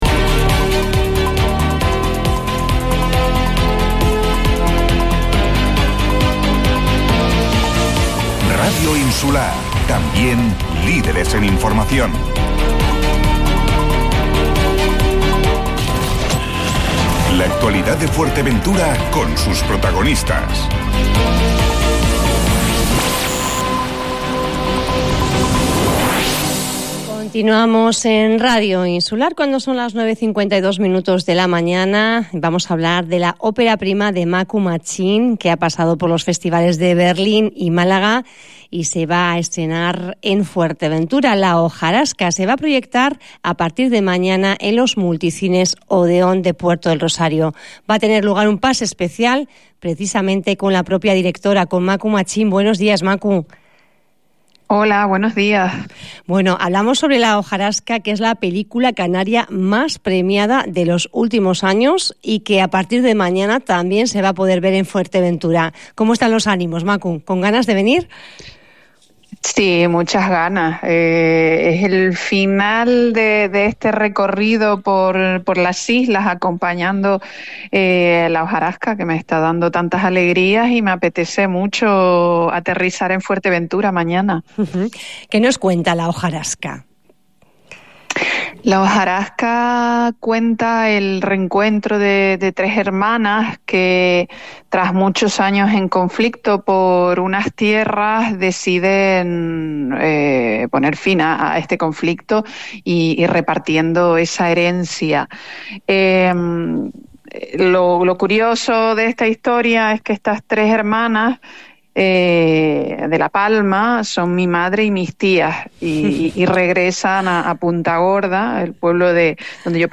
Esta mañana durante el programa La voz de Fuerteventura